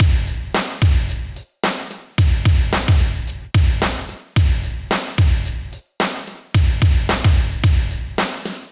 • 110 Bpm Breakbeat B Key.wav
Free drum groove - kick tuned to the B note. Loudest frequency: 3169Hz
110-bpm-breakbeat-b-key-5TX.wav